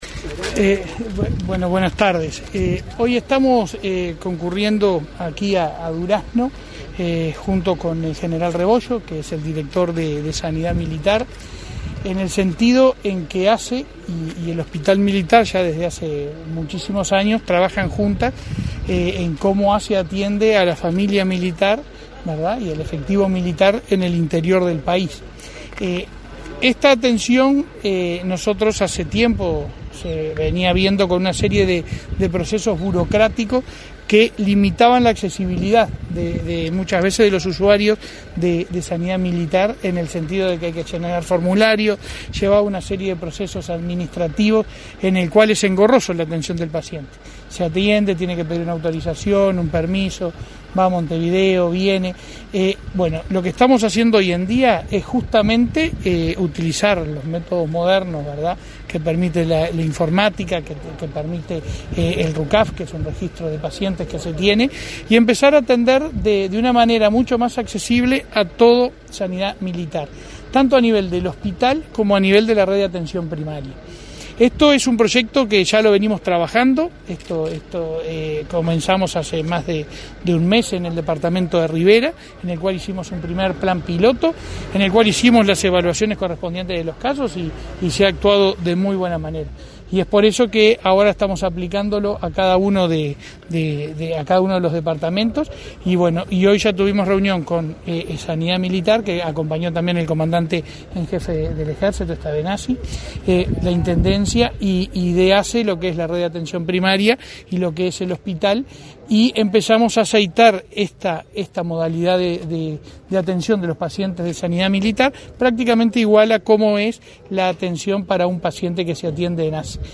Declaraciones de prensa del presidente de ASSE, Leonardo Cipriani, y del titular de Sanidad Militar, Hugo Rebollo
El objetivo de estas instancias es fortalecer las prestaciones que ASSE brinda al personal militar en todo el país. Tras el evento, los titulares de ASSE, Leonardo Cipriani, y de Sanidad Militar, Hugo Rebollo, realizaron declaraciones a la prensa.